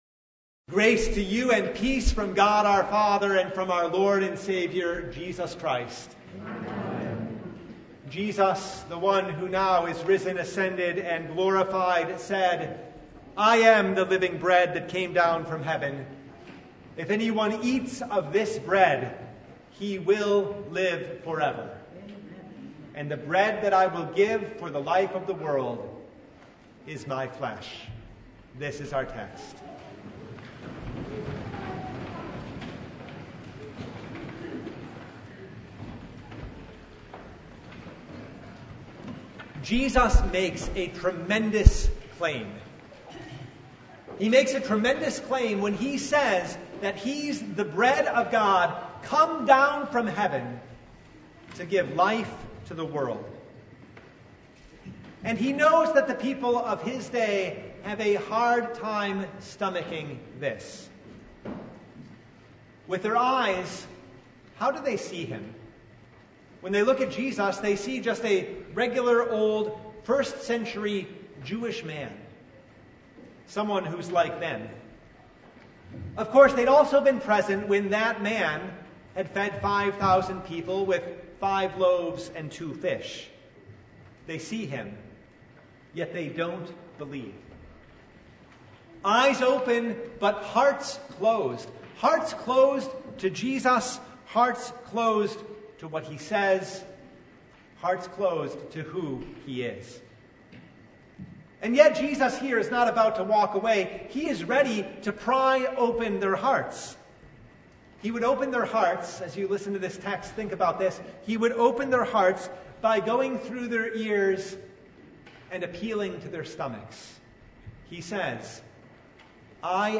Download Files Notes Topics: Sermon Only « More Bread?